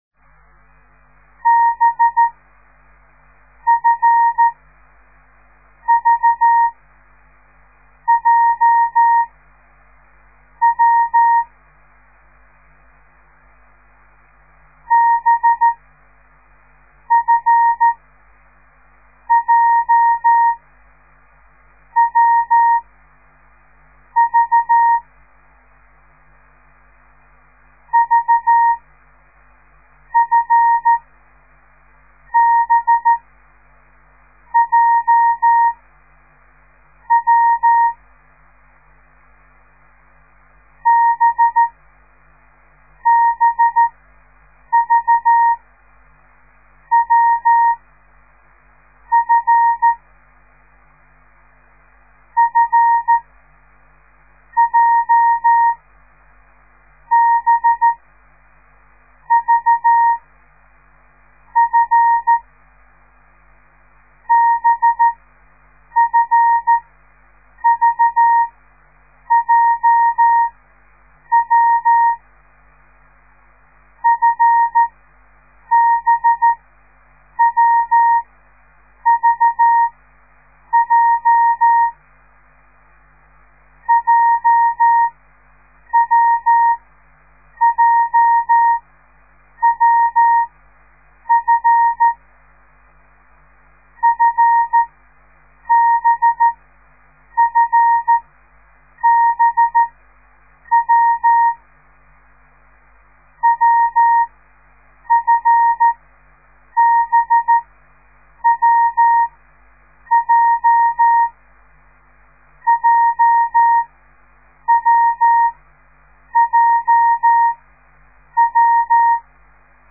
De geluidsfiles bestaan uit geseinde text in letter of cijfergroepen , steeds groepen van 5 tekens en iedere les bestaat uit 25 groepen
De letter B dah-di-di-dit  / de letter F di-di-dah-dit / de letter V di-di-di-dah / de letter J di-dah-dah-dah / de letter W di-dah-dah